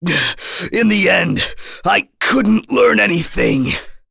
Chase-Vox_Dead.wav